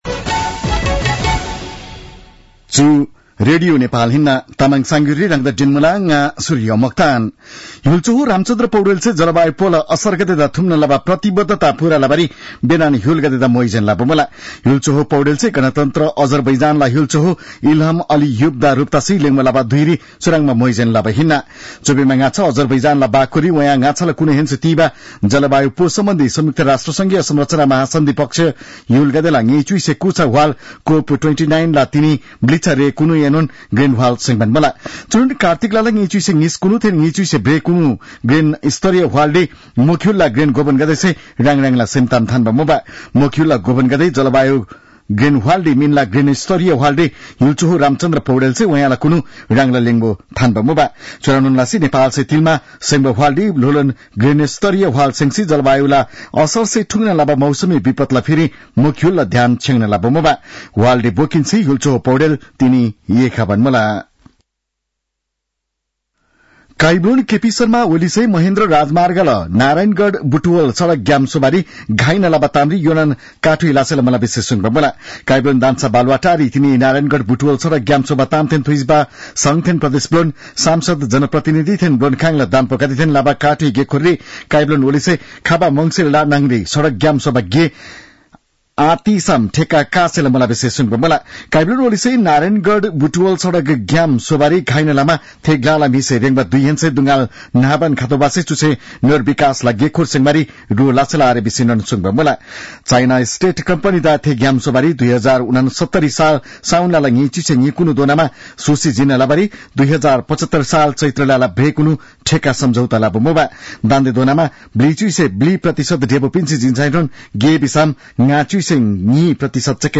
तामाङ भाषाको समाचार : ३० कार्तिक , २०८१
Tamang-news-7-29.mp3